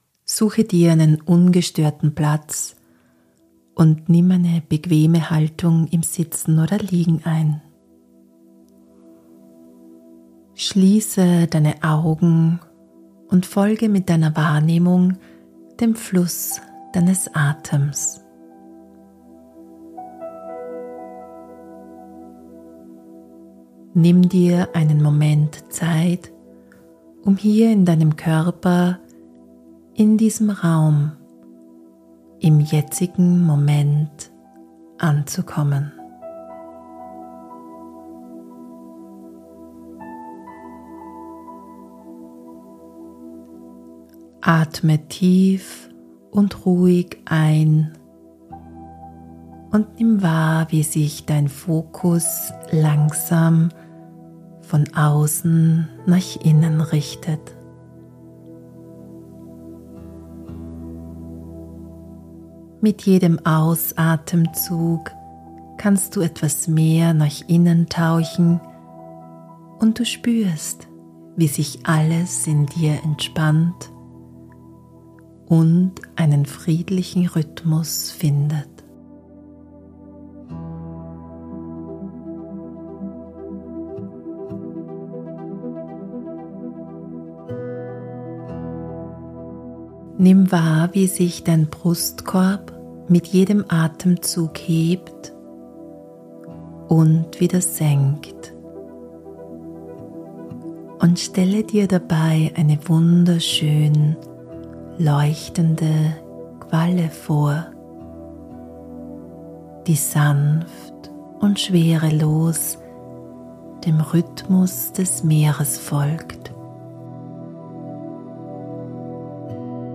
Diese geführte Meditation unterstützt dich dabei, dein Energiefeld bewusst wahrzunehmen und ein Schutzschild um dich herum aufzubauen. Du weitest dein Feld und erschaffst einen Raum, in dem du sicher, frei und in deiner Mitte bleiben kannst – unabhängig davon, was im Außen geschieht.